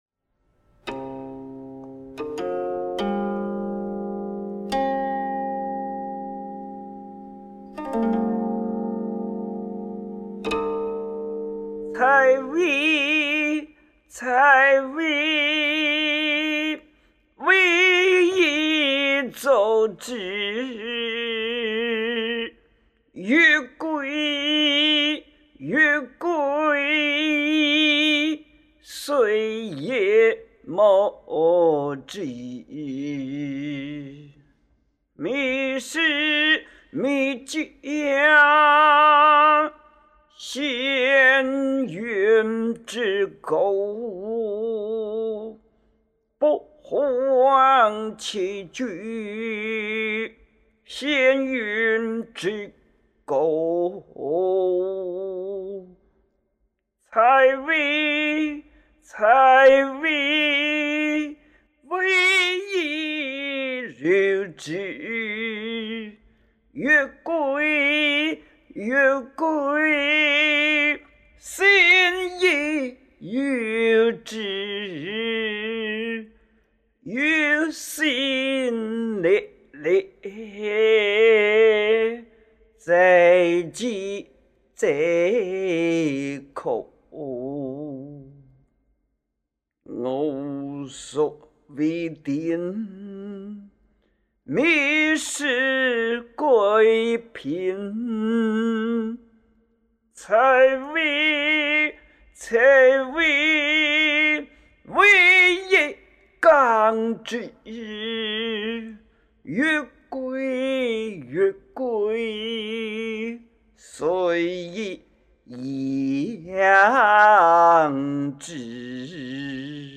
12.22 采薇 – 古琴